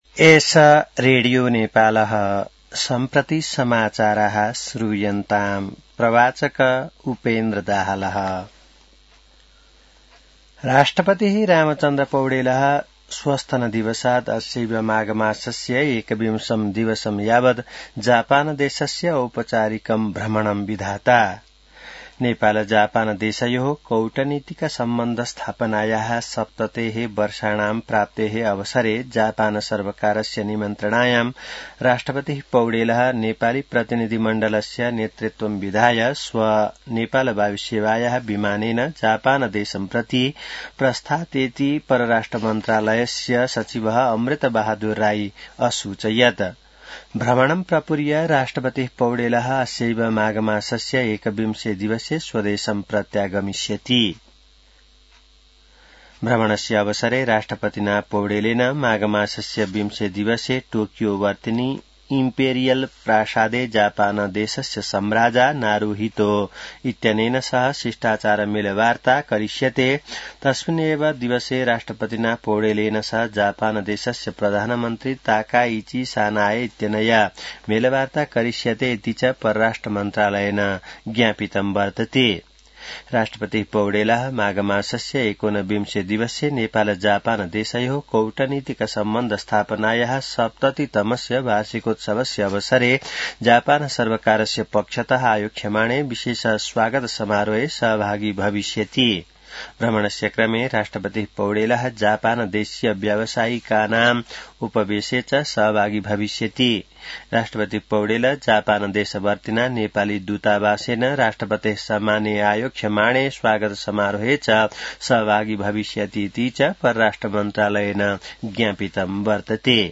An online outlet of Nepal's national radio broadcaster
संस्कृत समाचार : १७ माघ , २०८२